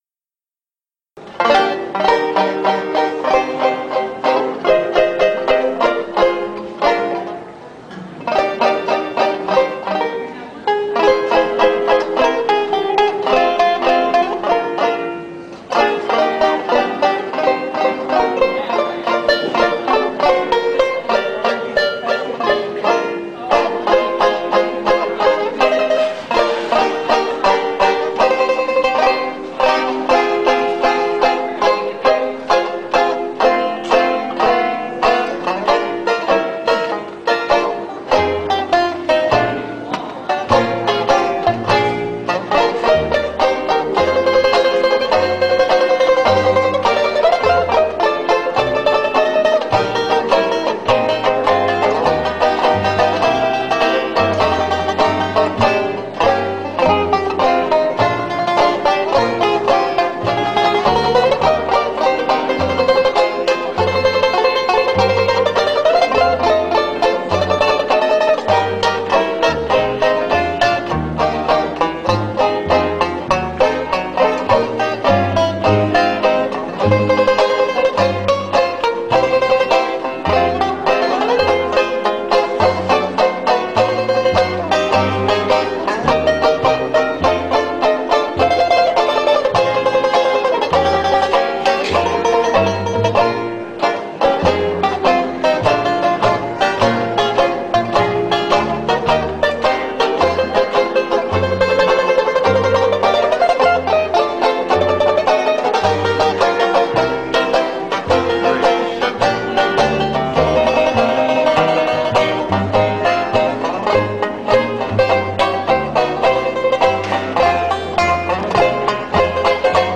8-beat intro.
This song is in the key of Eb.